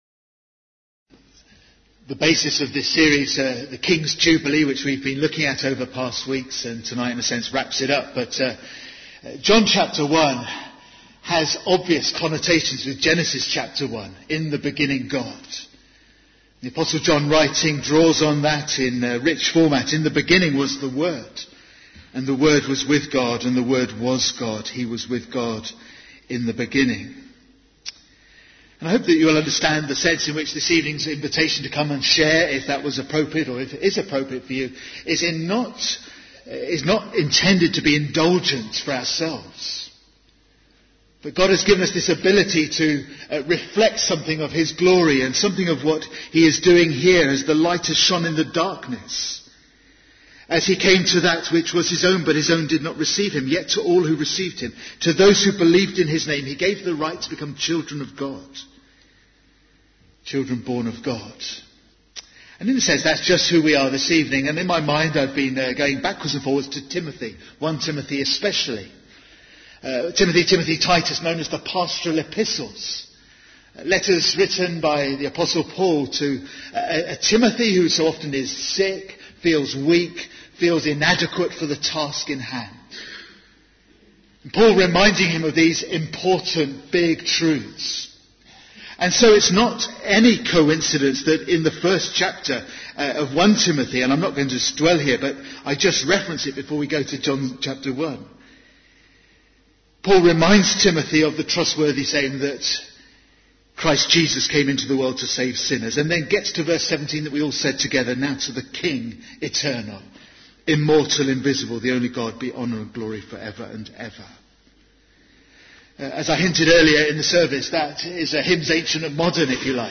Bible Text: John 1:1-18 | Preacher